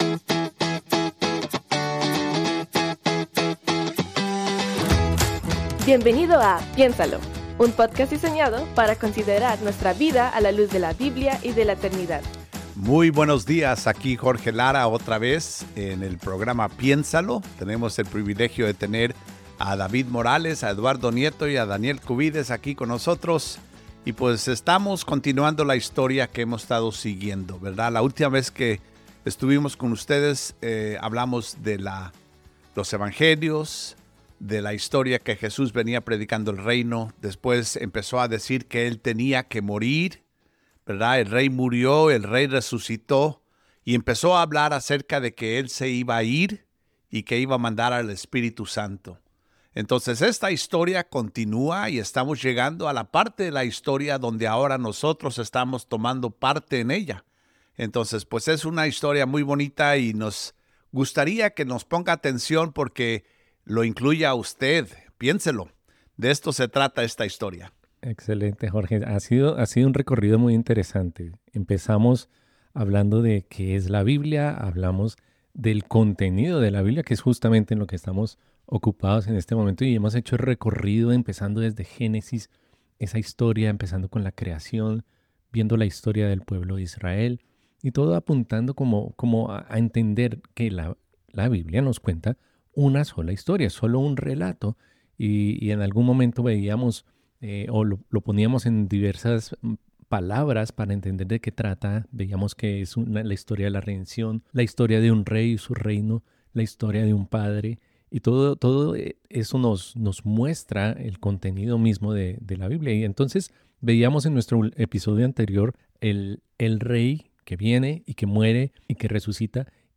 estarán conversando acerca del contenido del libro de los Hechos y las cartas en el Nuevo Testamento.